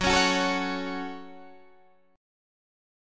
Gbm chord